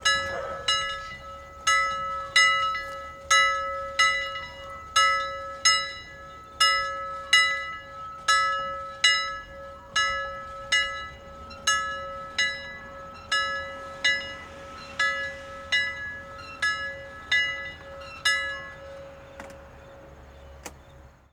Sound Effect
Medium Bell Ringing Near
Medium_Bell_Ringing_Near.mp3